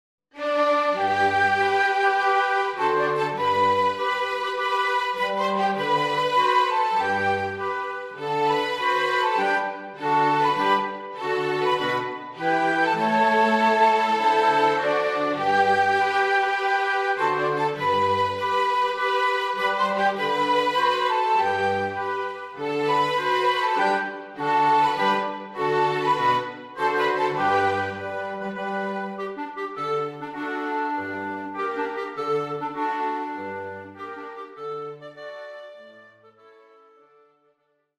Flexible Ensemble (Woodwind, Brass and String instruments)